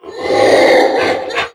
c_goril_dead.wav